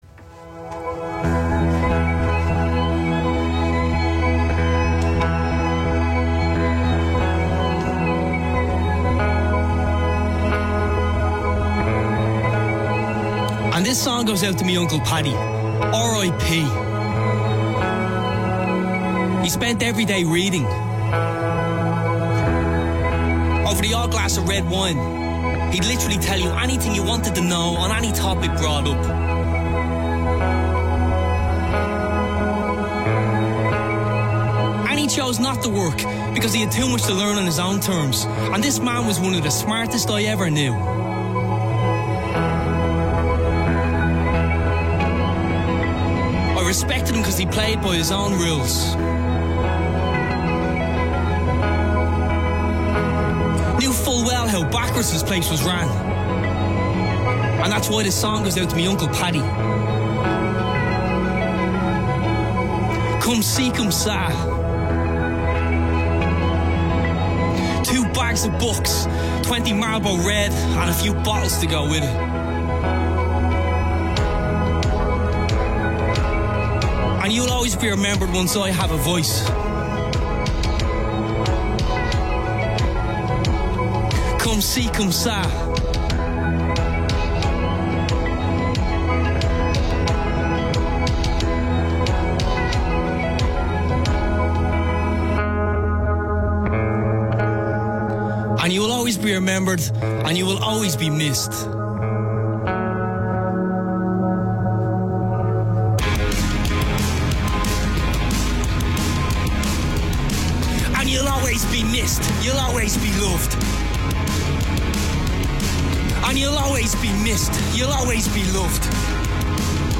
in session
Irish Rapper/Punk/Avant Garde artist
it’s visceral but at the same time cathartic
For all you may blanche at listening to these live pieces